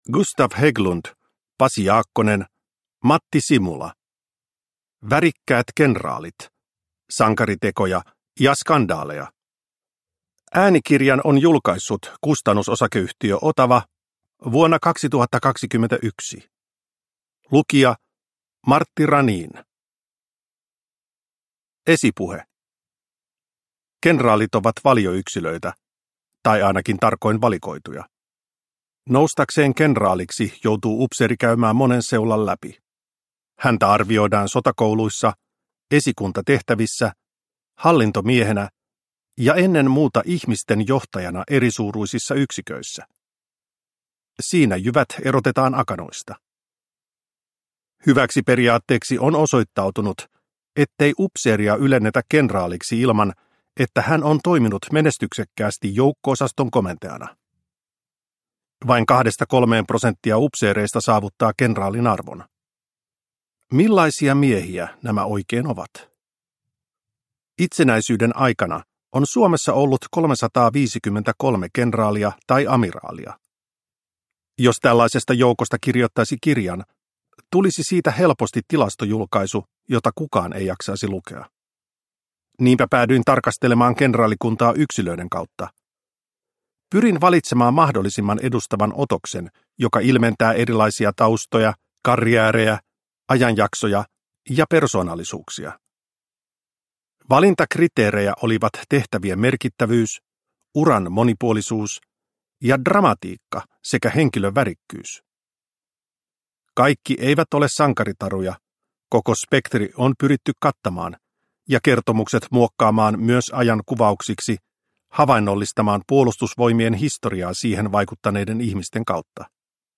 Värikkäät kenraalit – Ljudbok – Laddas ner